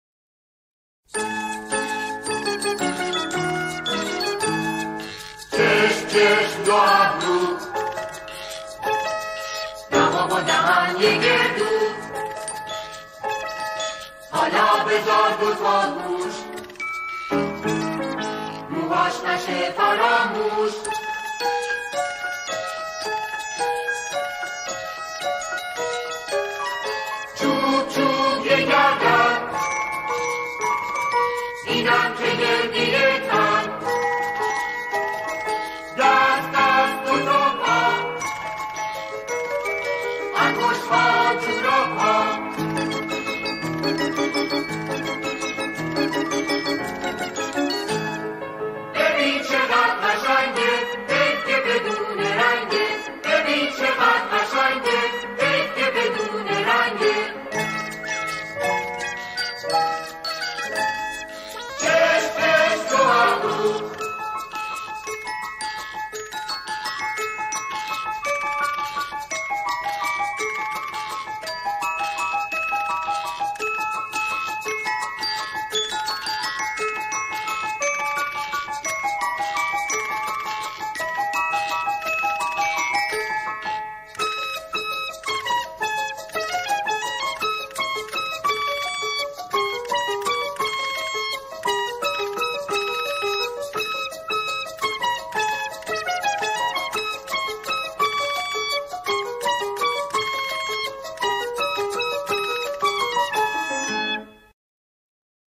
سرود کودکانه